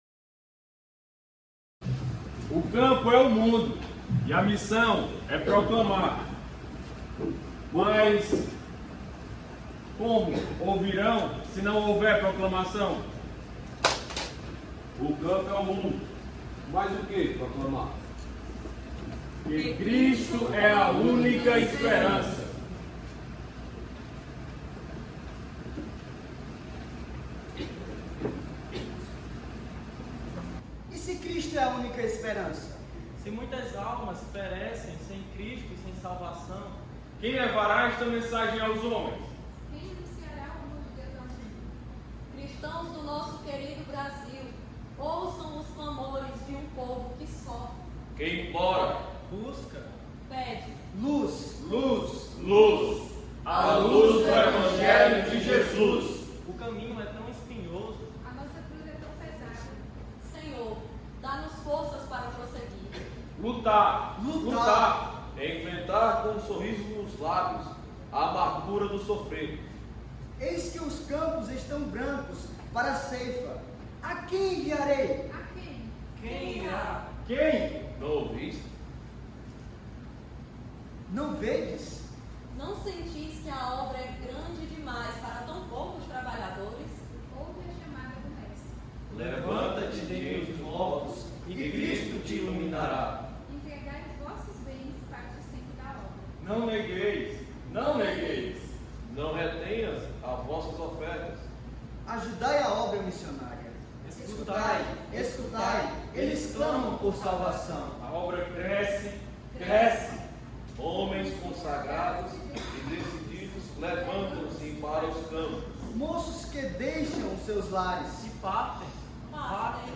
1ª Conferência Missionária - Jogral ''Cristo é a única esperança'' (adaptado) e ''Voltará''
Igreja Batista Luz do Mundo, Fortaleza/CE.